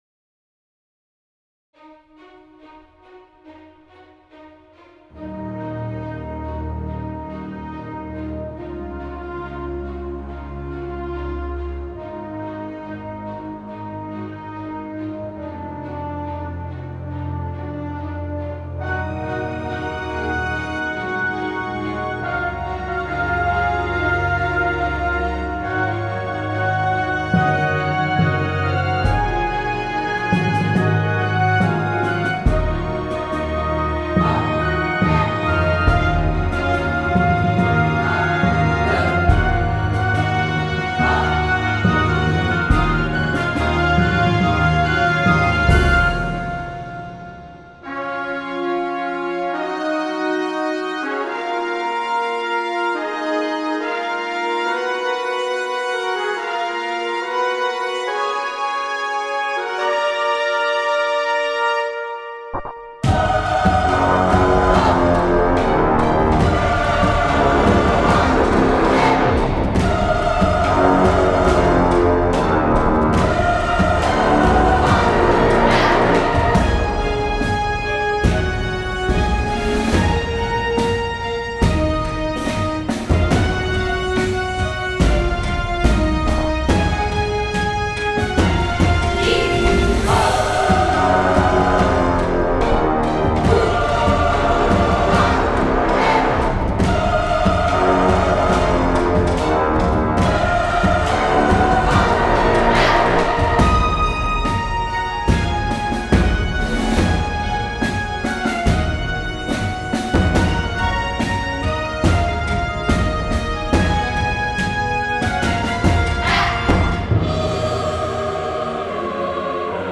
Epic, Suspense - Cinematic / Orchestral